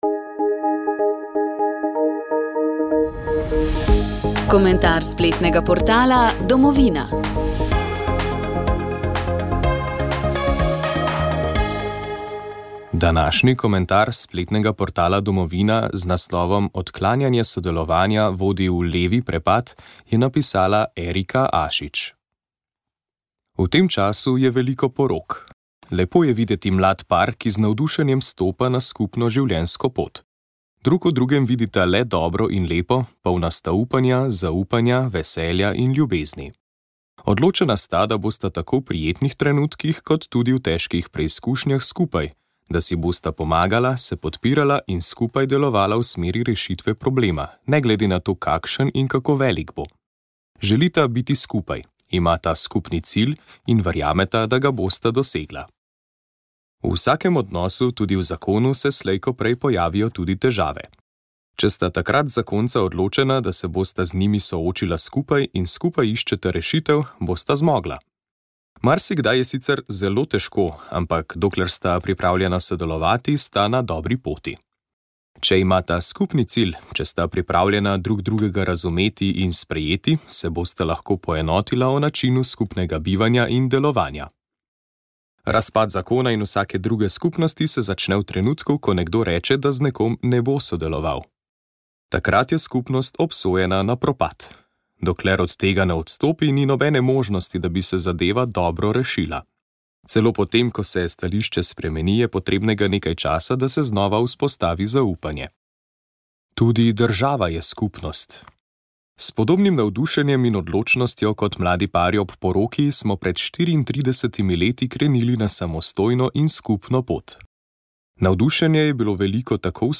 Pridiga škofa Franca Šuštarja na Stični mladih 2018